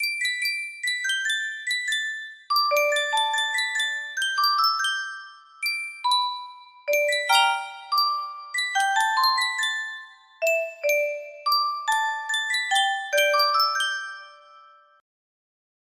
Yunsheng Caja de Musica - Duerme negrito 6501 music box melody
Full range 60